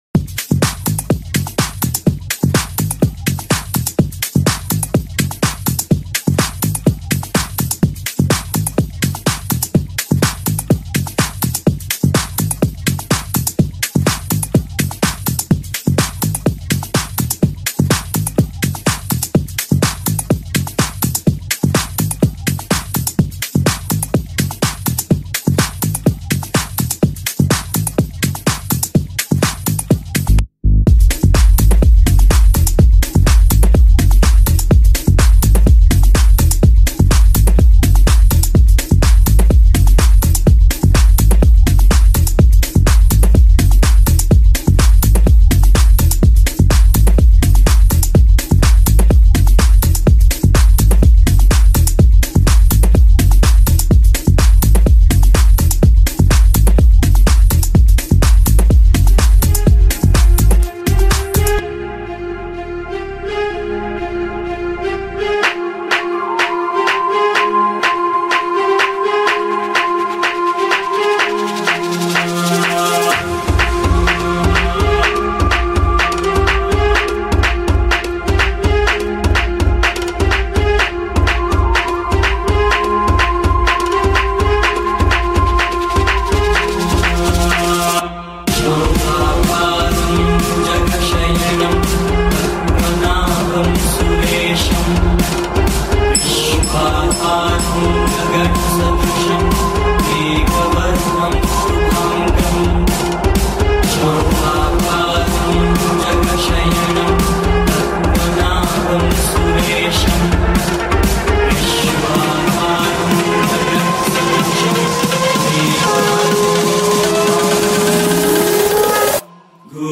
Genre - Tech House
BPM - 125